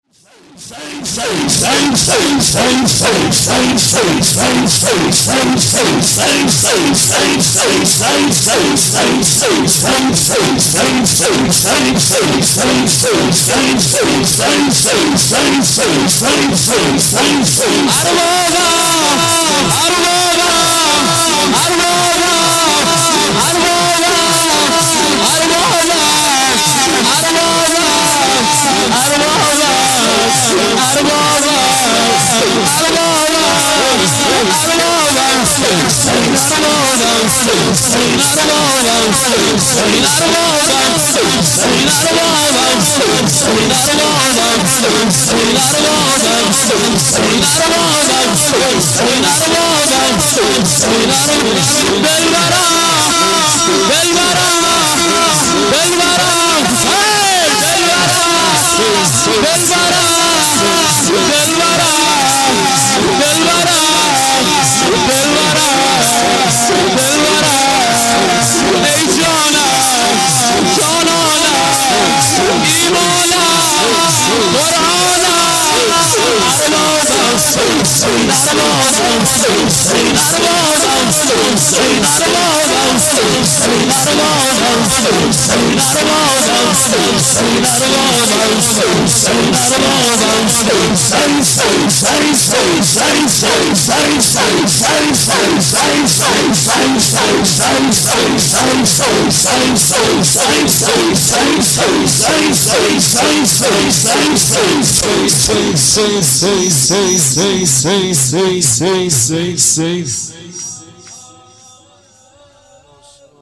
شور و ذکر